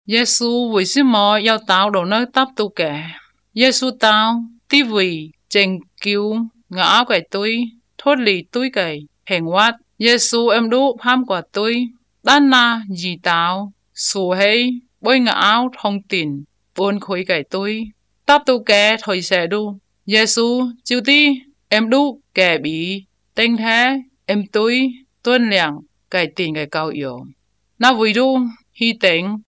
These are recorded by mother-tongue speakers
Bible Overview, Bible Stories, Discipleship